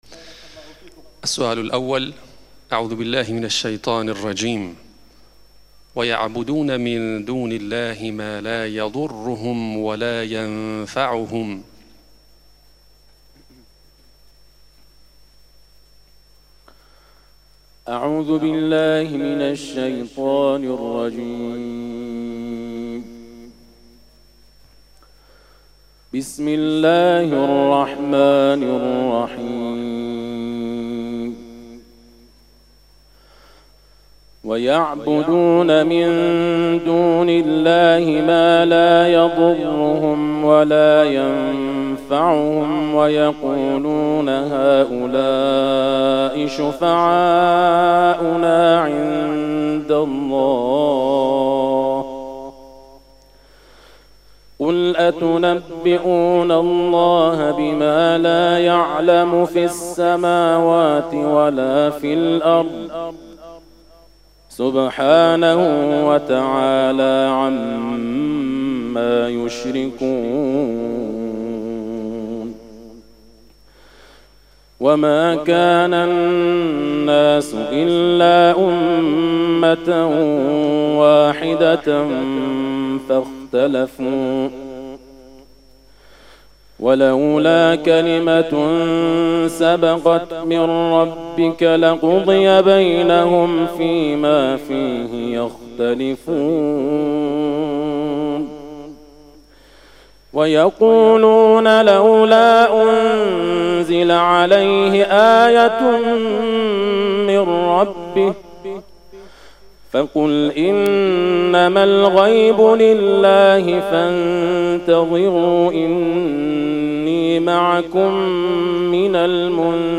آخرین تلاوت حافظ ایرانی در فینال مسابقات بین‌المللی صوت - تسنیم
در ادامه صوت تلاوت ایشان را می‌شنوید.